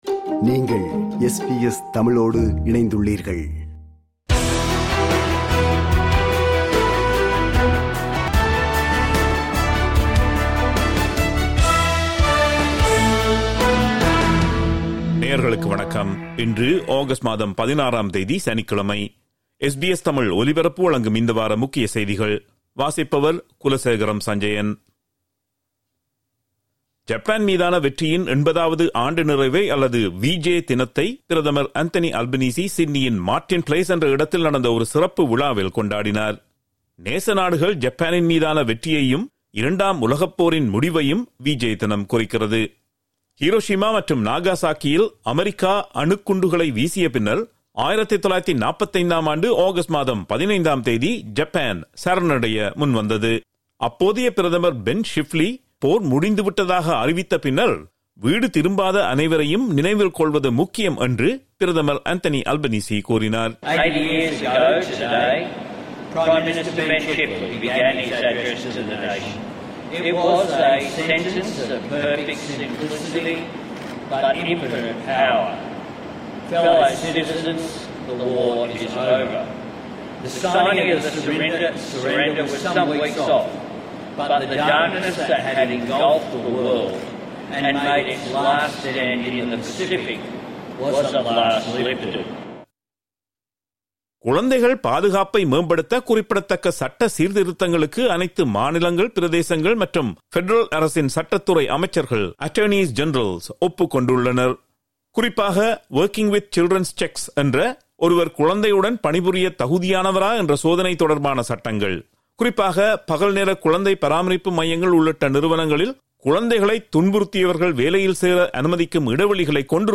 ஆஸ்திரேலிய, உலக செய்திகளின் இந்த வார தொகுப்பு